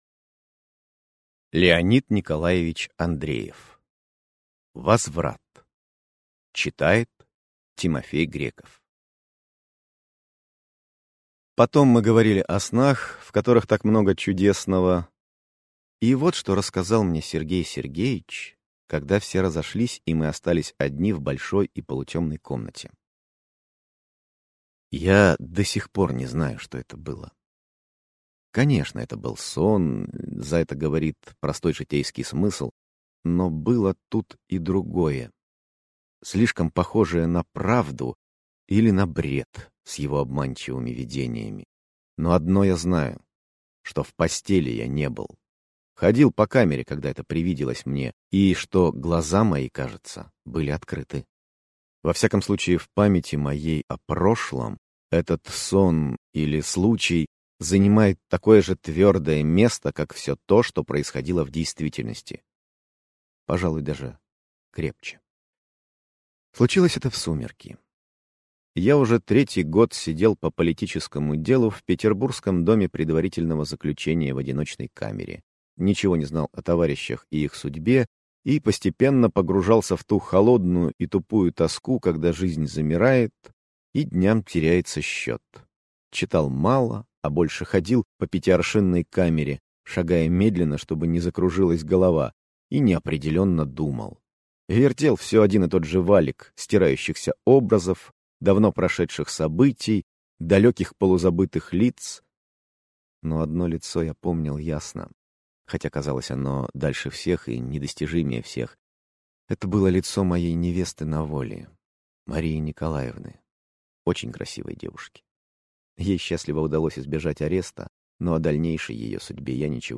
Aудиокнига Возврат Автор Леонид Андреев Читает аудиокнигу